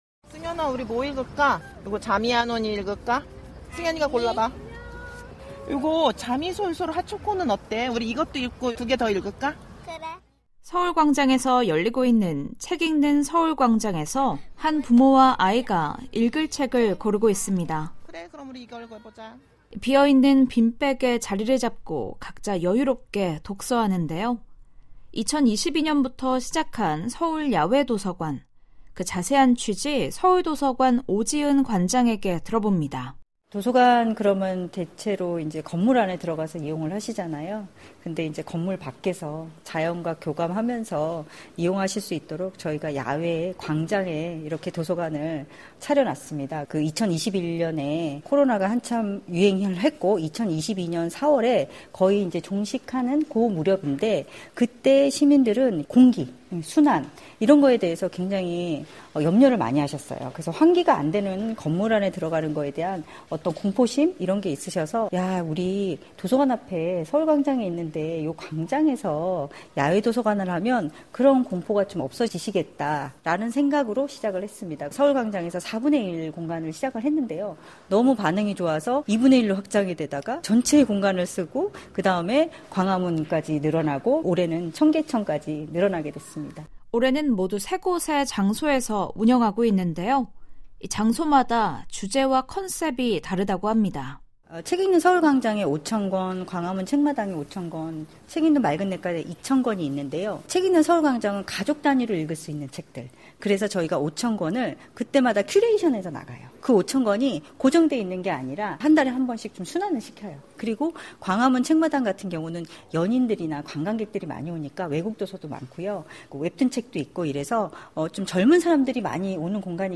서울도서관이 주최하는 세계 최초 야외도서관이 현재 서울 곳곳에서 열리고 있습니다. 도서관을 밖으로 끌고 나와 책을 보며 하늘과 물, 자연과 함께 즐길 수 있는데요. 변화하는 한국의 모습을 살펴보는 ‘헬로서울’, 오늘은 '서울야외도서관, 책읽는 맑은냇가' 현장으로 안내해 드립니다.